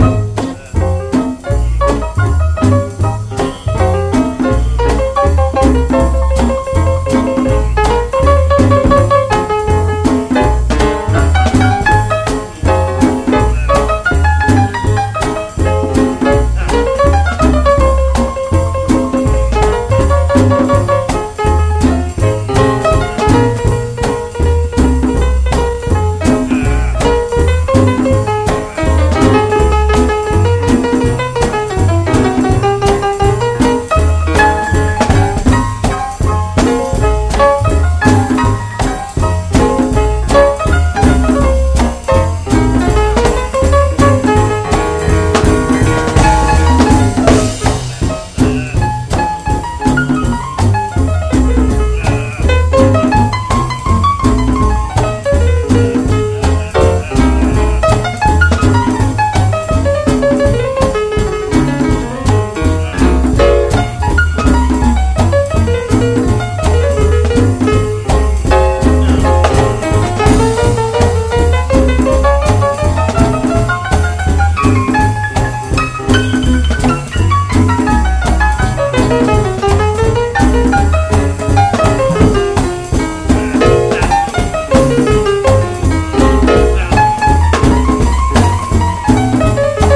JAZZ / MAIN STREAM / MODAL